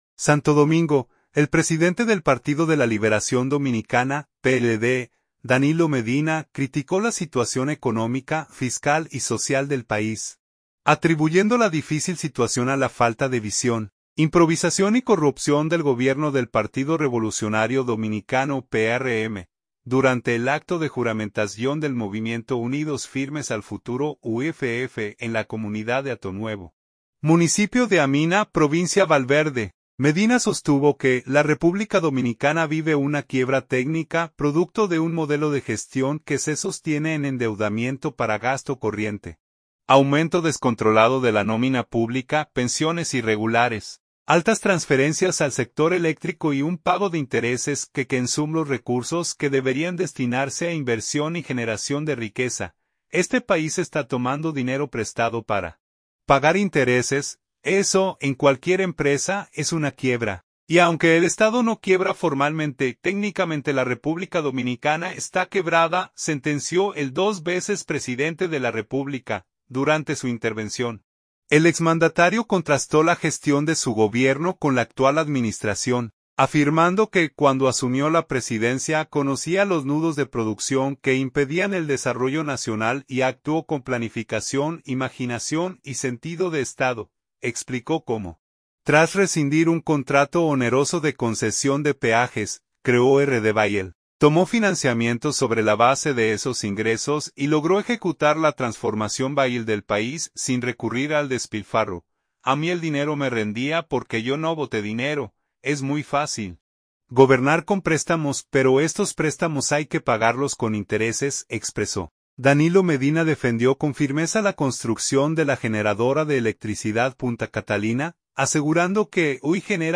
Durante el acto de juramentación del Movimiento Unidos Firmes al Futuro (UFF), en la comunidad de Hato Nuevo, municipio de Amina, provincia Valverde, Medina sostuvo que la República Dominicana vive una “quiebra técnica”, producto de un modelo de gestión que se sostiene en endeudamiento para gasto corriente, aumento descontrolado de la nómina pública, pensiones irregulares, altas transferencias al sector eléctrico y un pago de intereses que consume los recursos que deberían destinarse a inversión y generación de riqueza.